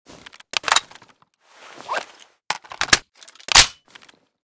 sig550_reload_empty.ogg.bak